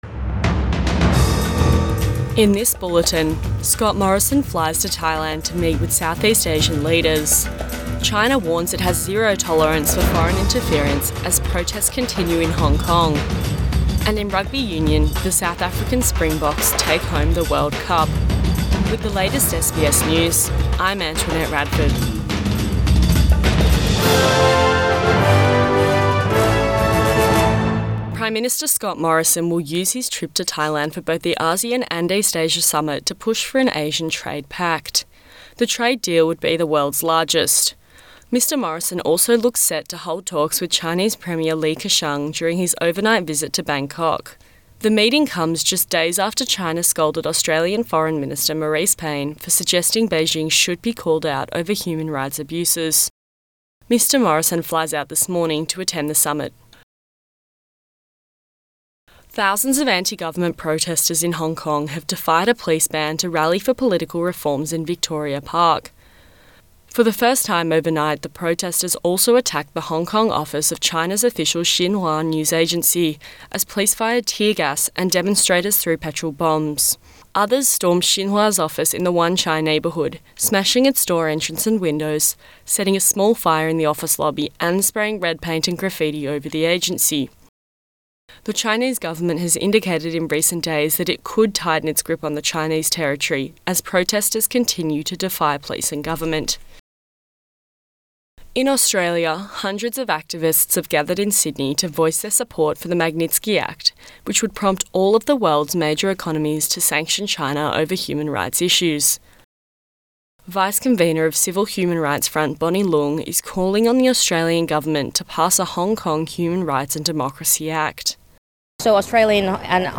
AM bulletin 3 November 2019